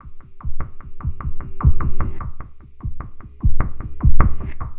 Abstract Rhythm 36.wav